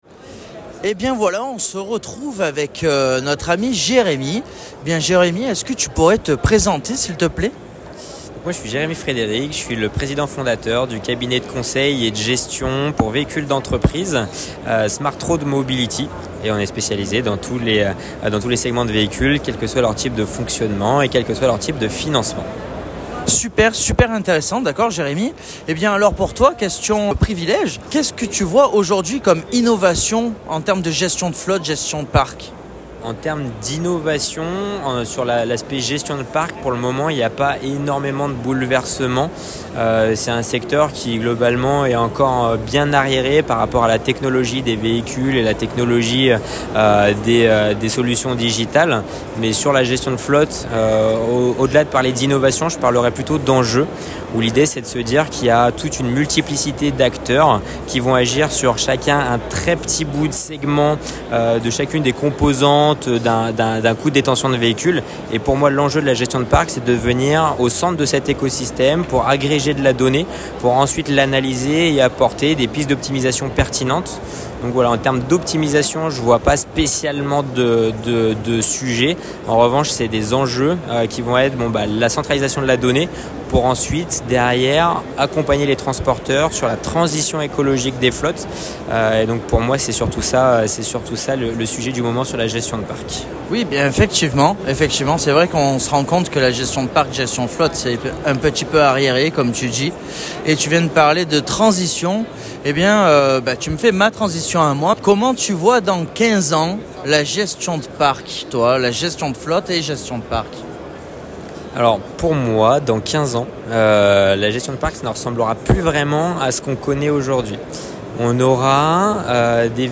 Le 23/11/2023 – EUREXPO Chassieu – SOLUTRANS
INTERVIEW